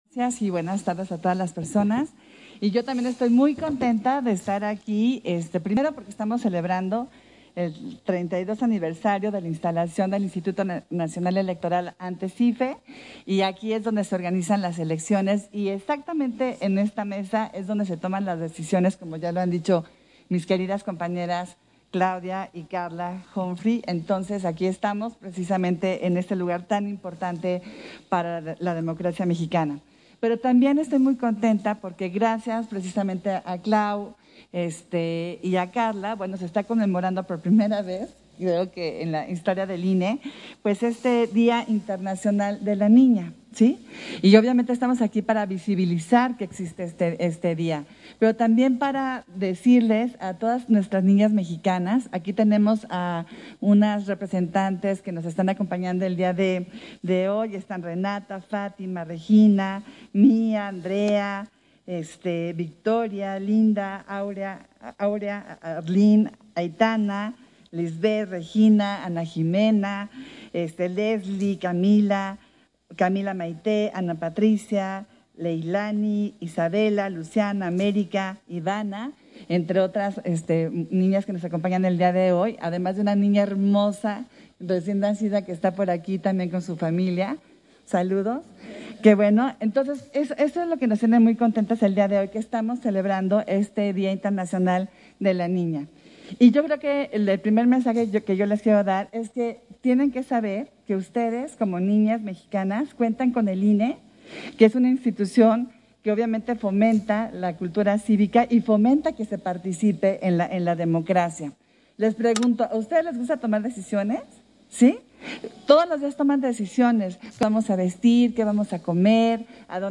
Intervención de Adriana Favela, en la conmemoración del Día Internacional de la Niña